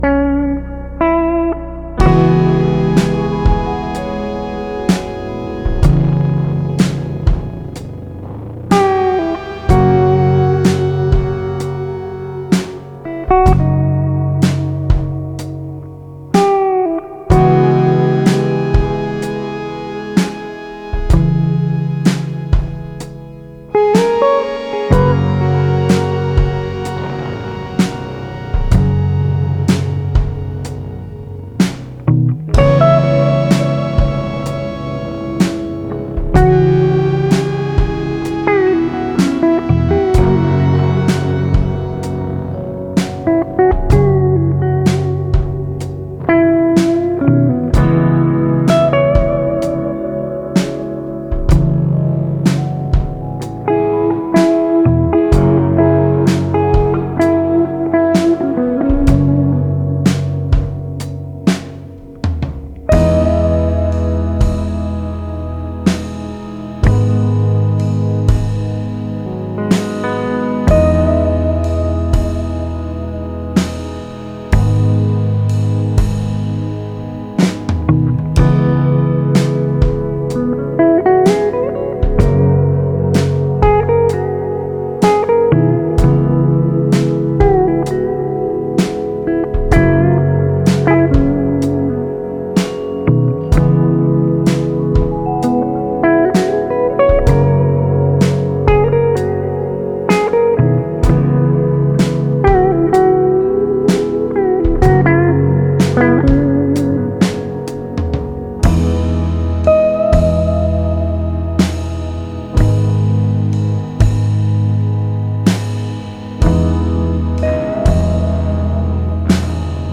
Genre: Folk Rock, Singer-Songwriter